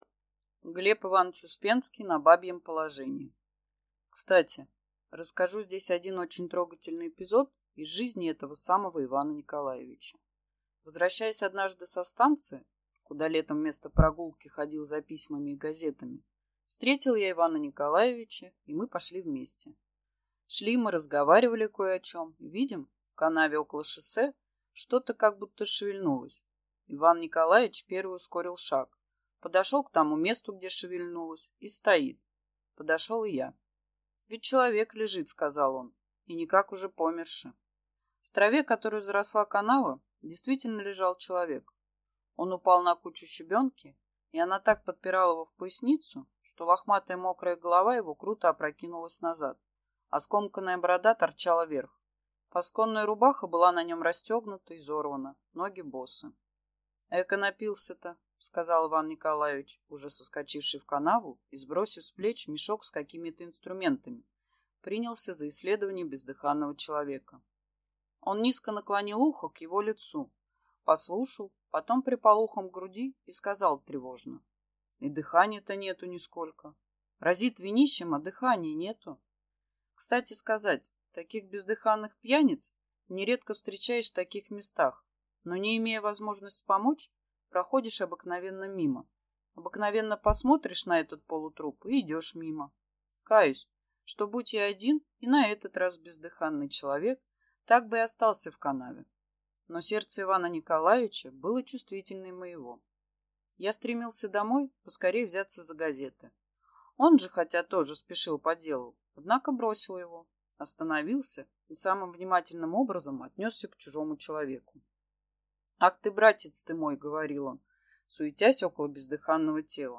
Аудиокнига На бабьем положении | Библиотека аудиокниг